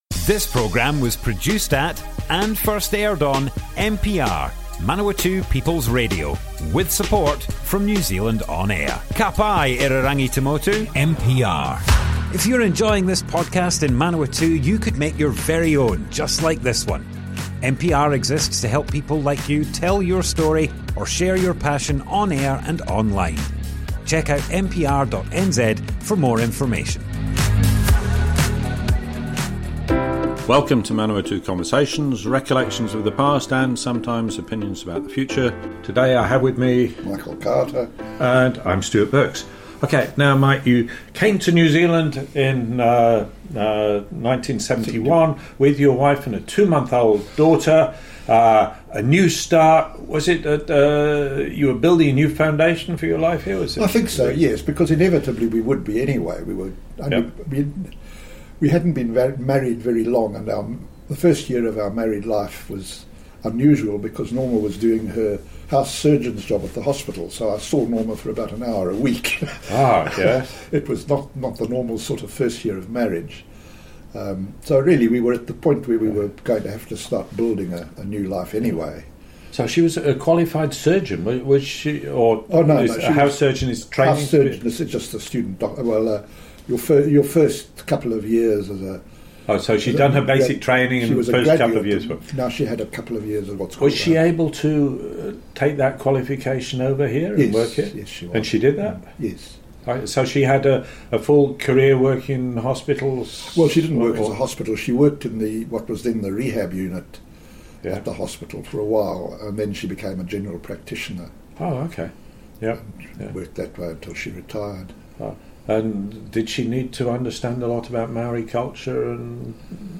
Manawatu Conversations More Info → Description Broadcast on Manawatu People's Radio, 1st August 2023.